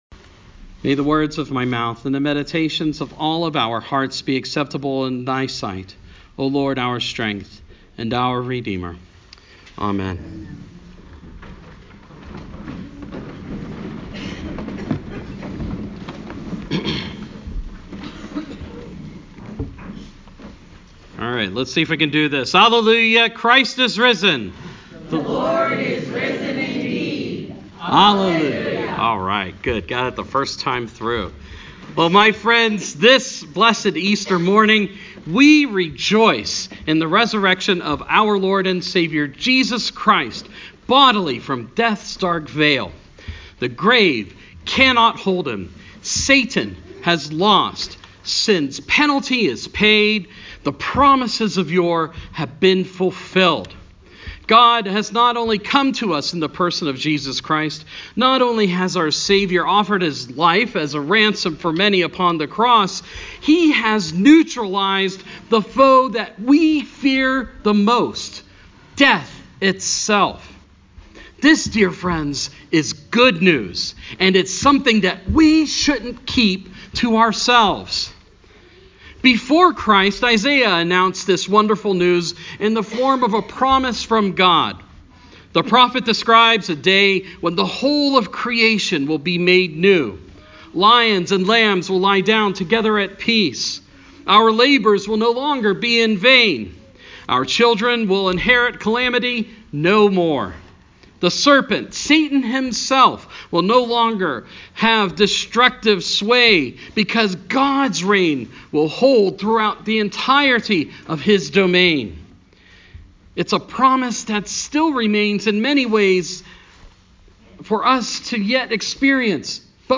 Sermon – Easter Sunday